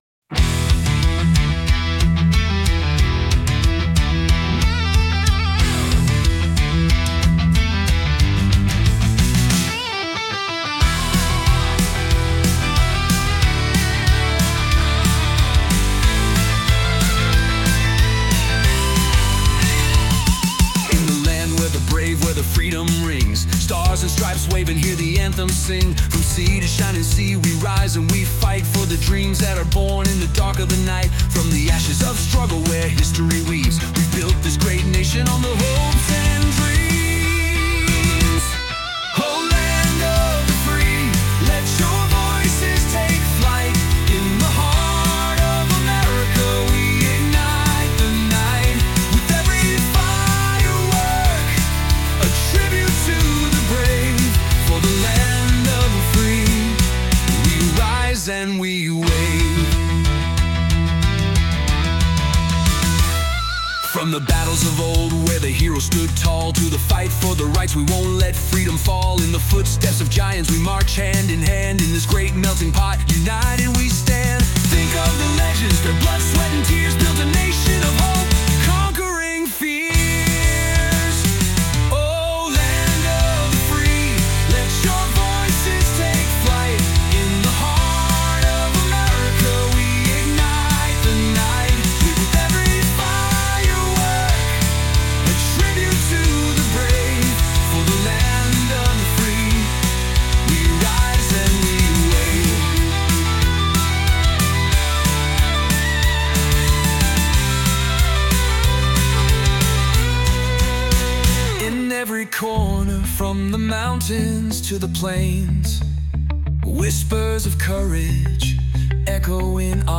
Patriotic Music